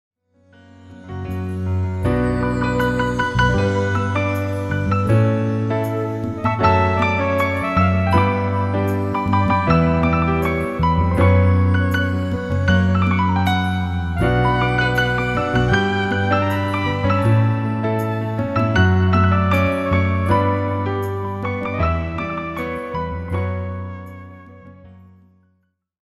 álbum instrumental